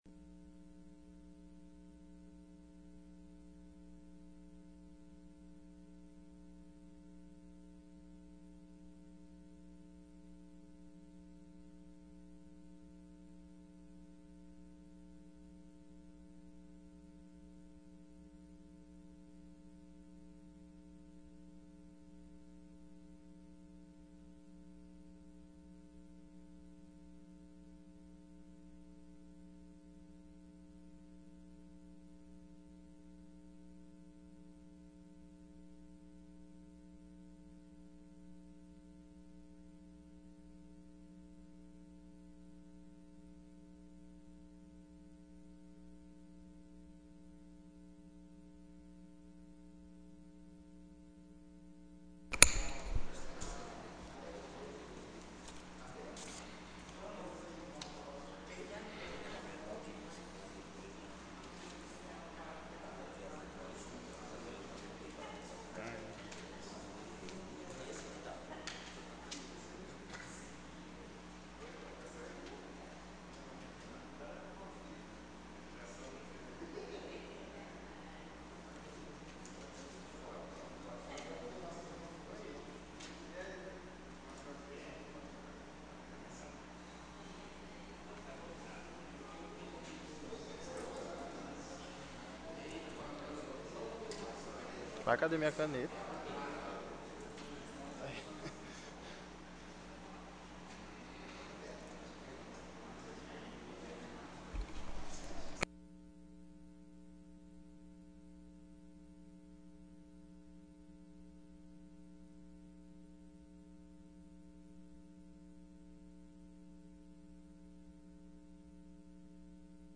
24ª SESSÃO ORDINÁRIA 06/12/2017